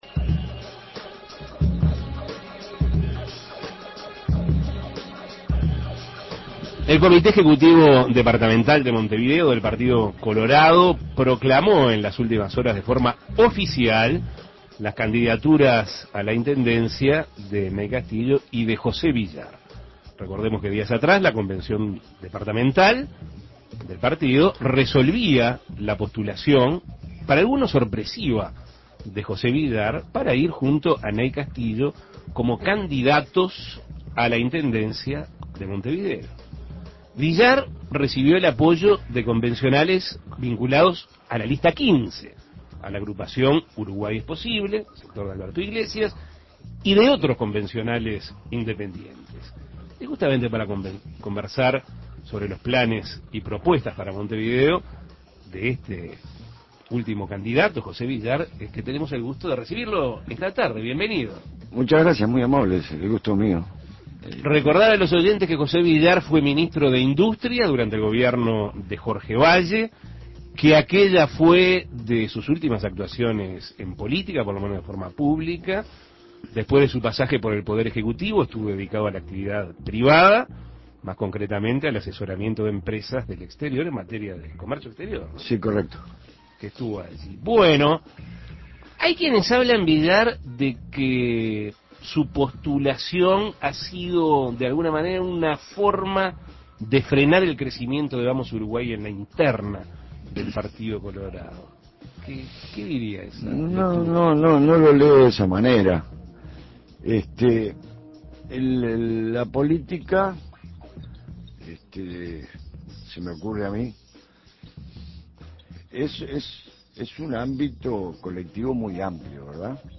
José Villar, candidato a la Intendencia de Montevideo por el Partido Colorado y ex ministro de Industria, dialogó sobre sus planes y propuestas para la capital del país, de cara a los comicios del próximo 9 de mayo, luego de varios años de inactividad política, en los que se dedicó a la actividad privada, más concretamente al asesoramiento de empresas extranjeras en materia de comercio exterior. Escuche la entrevista.